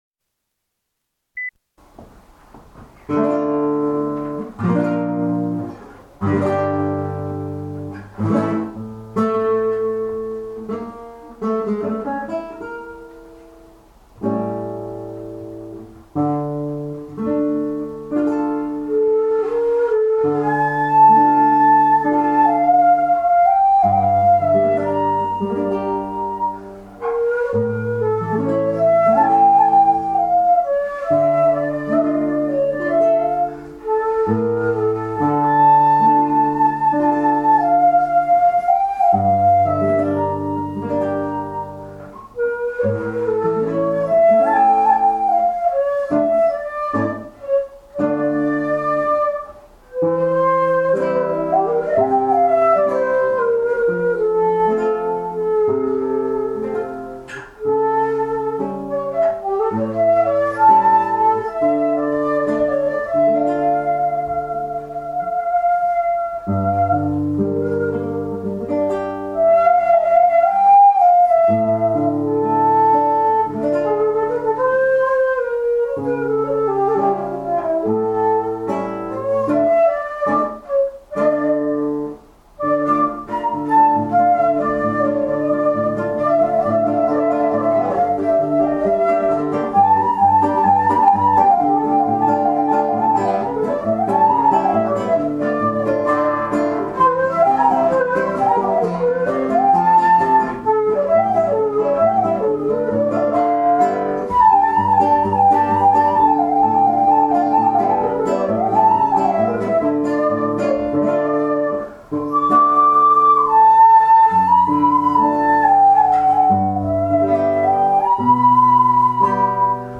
なぜか、ジプシー音楽には日本人に親近感をいだかせる何かがあるようです。